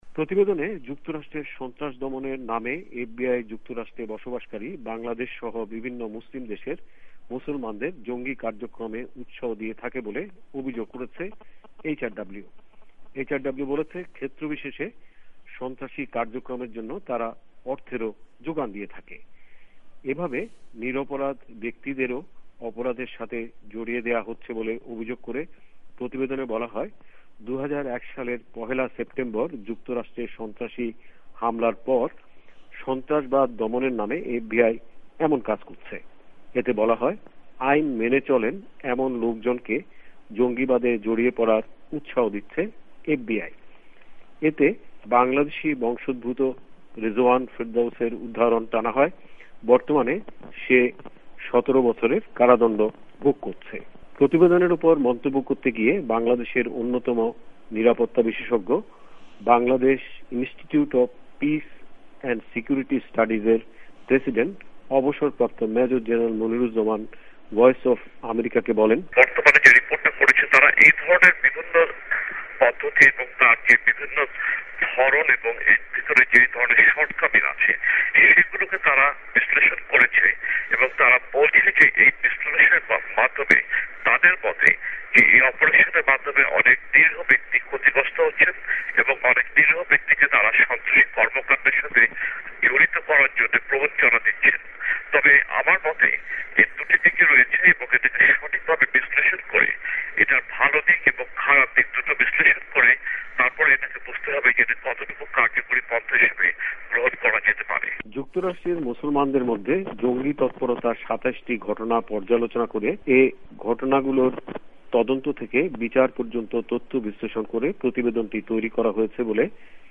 ভয়েস অব আমেরিকার ঢাকা সংবাদদাতাদের রিপোর্ট